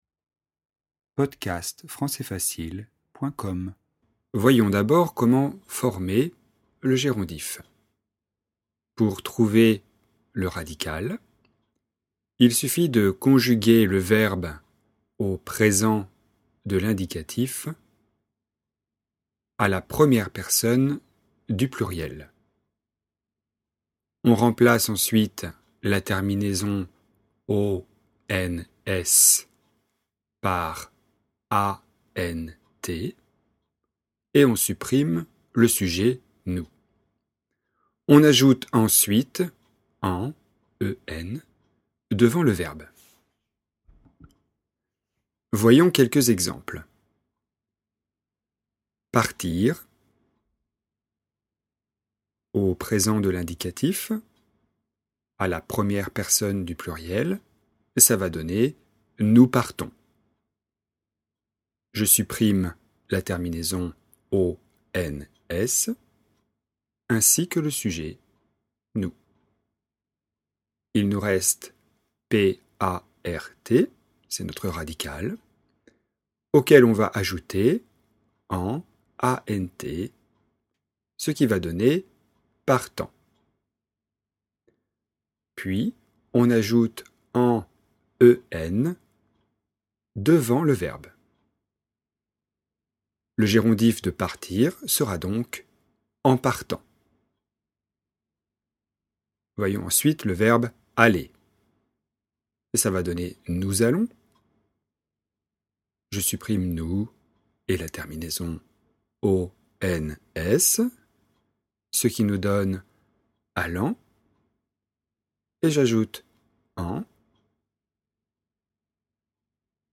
Gérondif présent - leçon